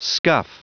Prononciation du mot scuff en anglais (fichier audio)
Prononciation du mot : scuff